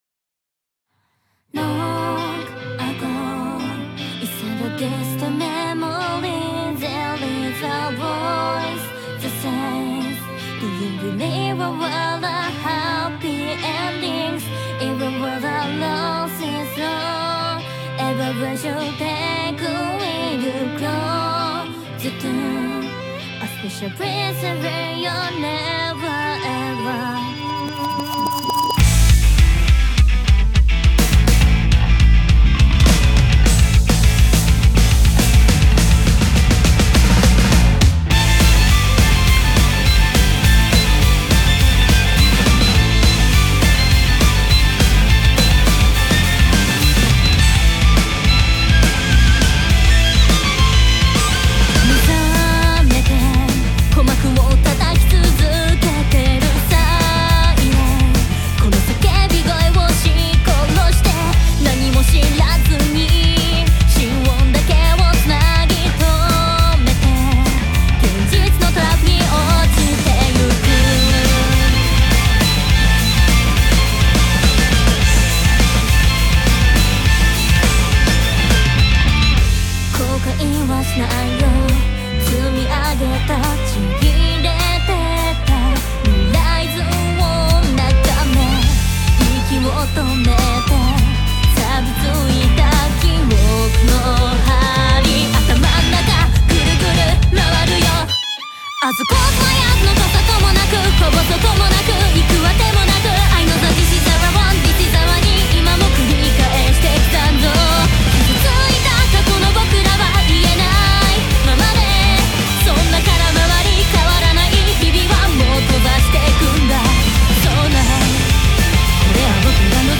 BPM75-206
Audio QualityCut From Video